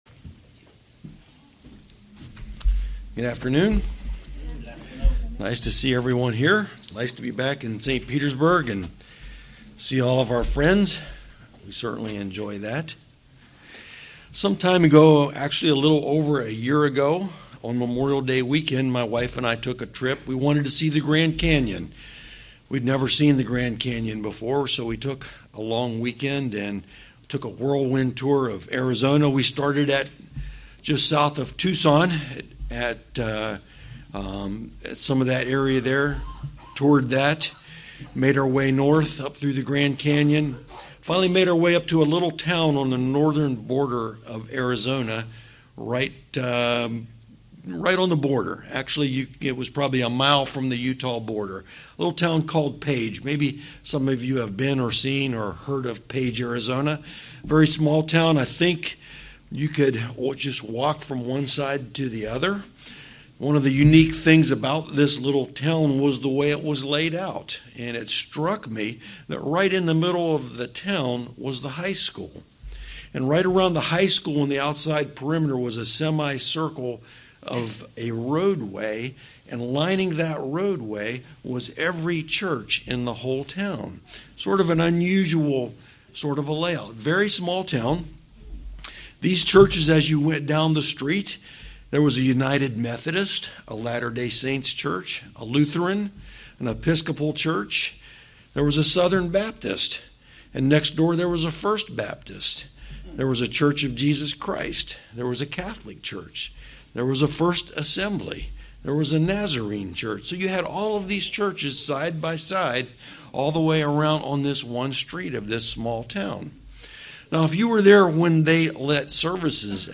Sermon
Given in St. Petersburg, FL